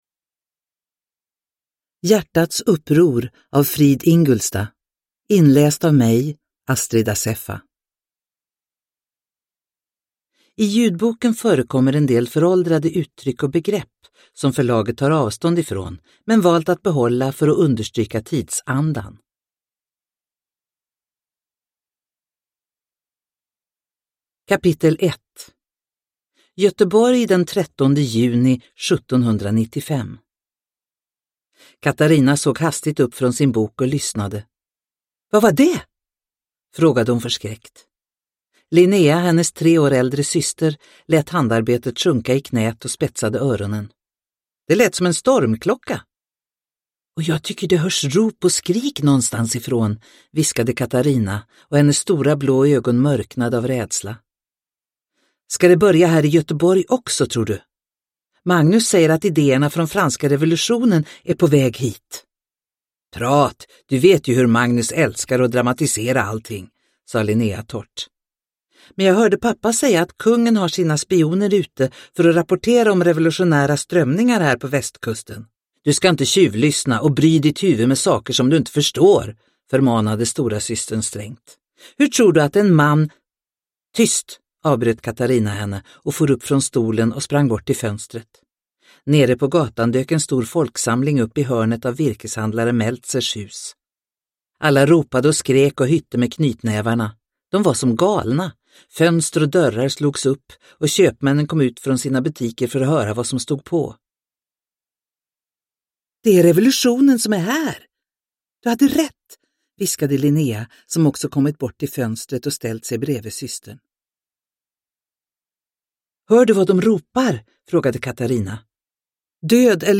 Hjärtats uppror – Ljudbok – Laddas ner